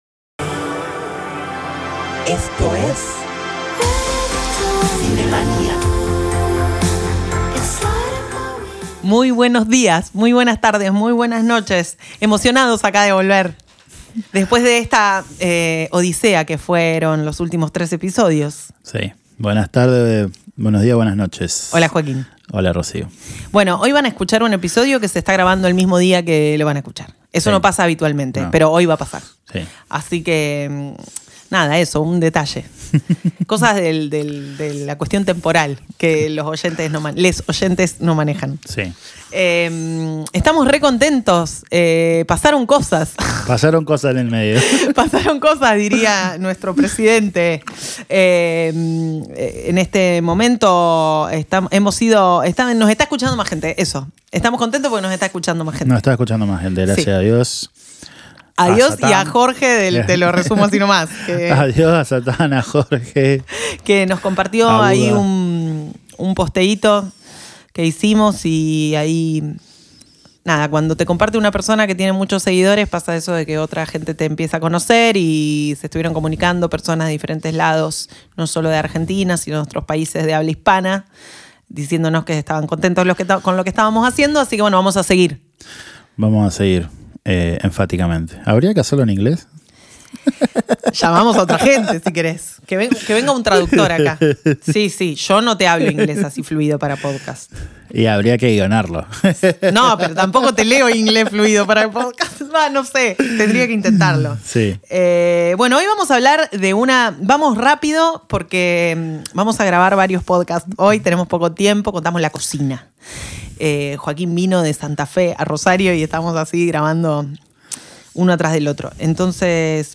Una conversación devenida en entrevista que nos hace navegar en las aguas profundas del animé.